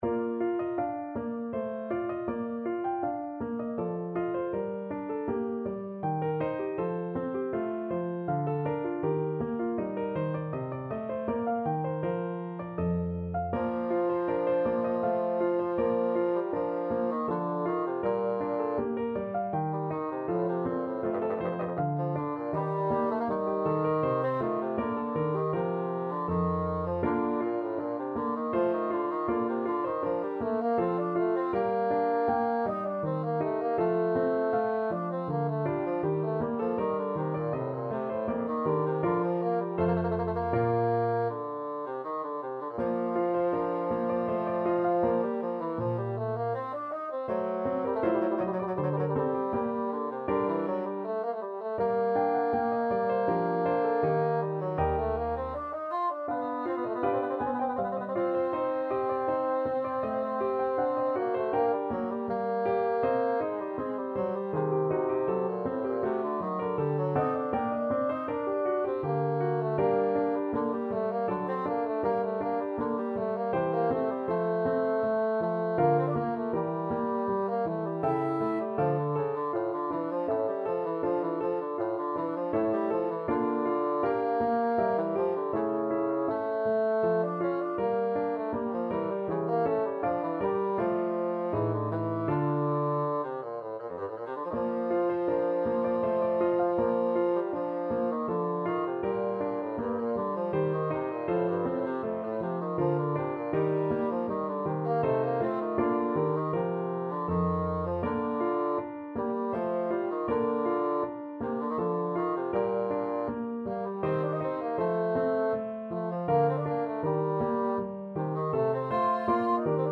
Bassoon
Bb major (Sounding Pitch) (View more Bb major Music for Bassoon )
3/4 (View more 3/4 Music)
Andante
Classical (View more Classical Bassoon Music)